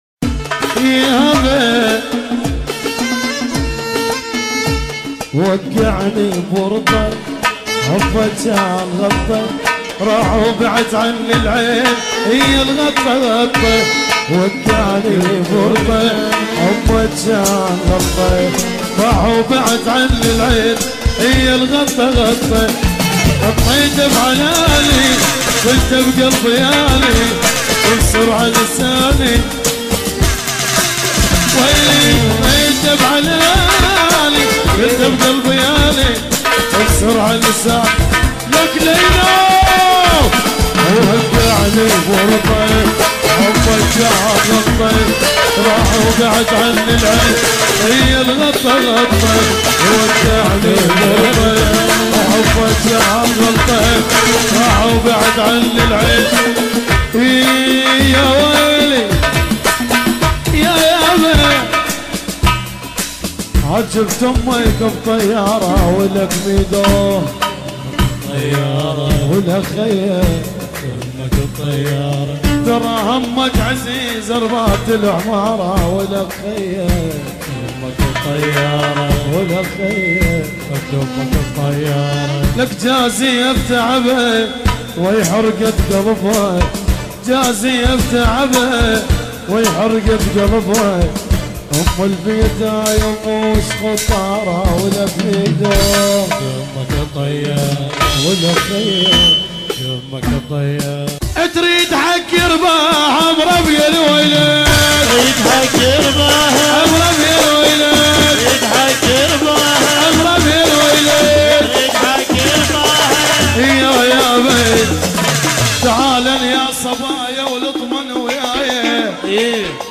حفلة